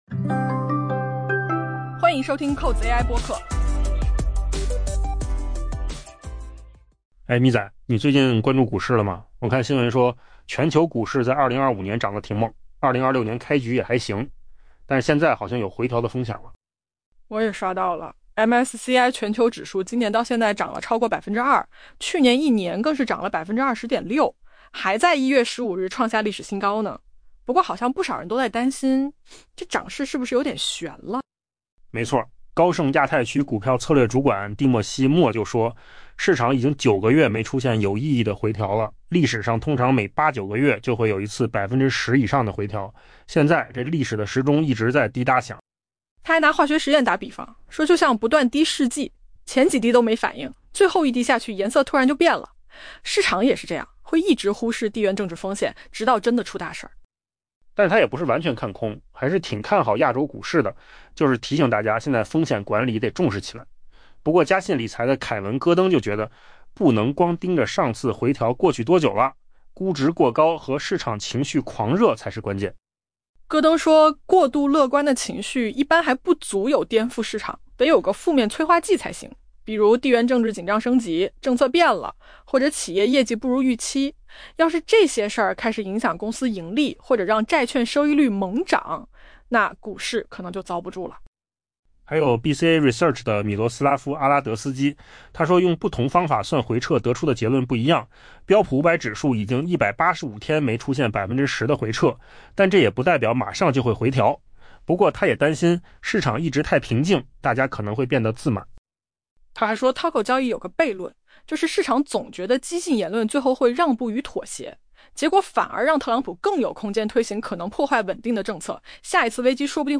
AI播客：换个方式听新闻 下载mp3
音频由扣子空间生成
全球股市回调风险升温？专家激辩.mp3